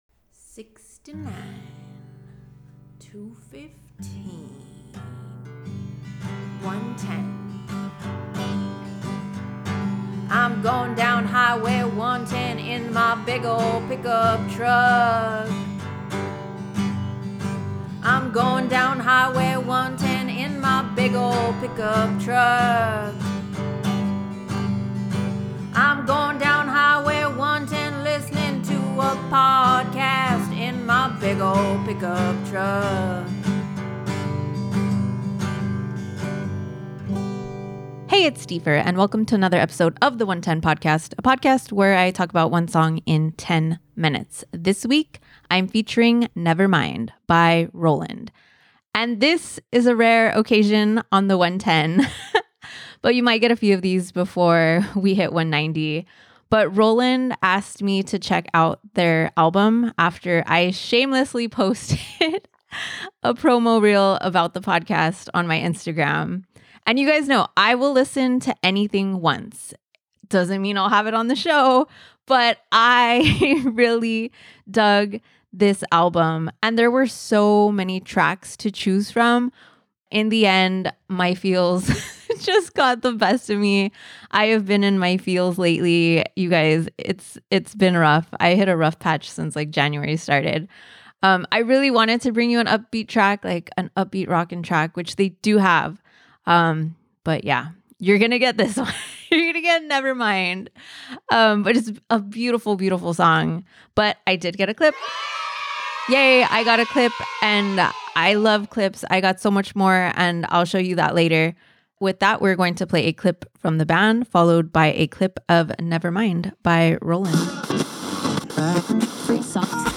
The 110 Podcast One song, ten minutes.